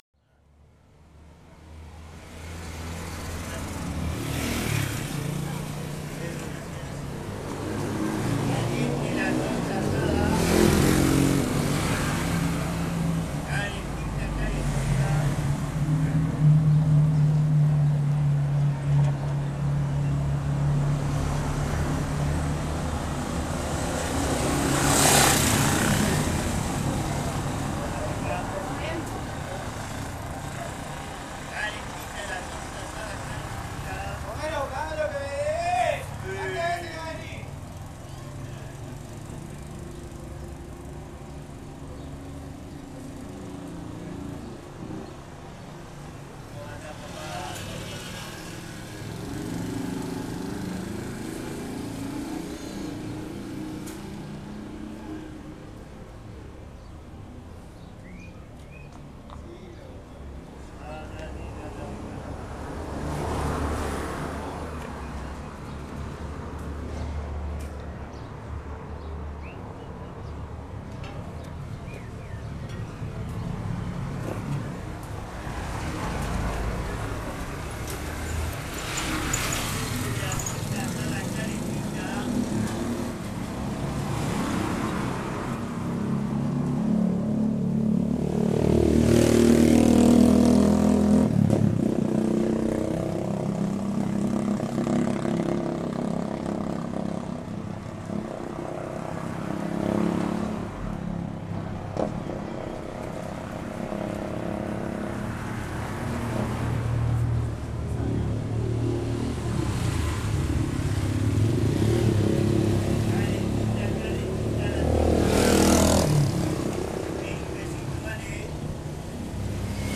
psr-puesto-de-torta-asada-cisneros-y-poeta-vicente-medina.mp3